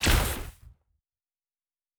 Weapon 15 Shoot 1.wav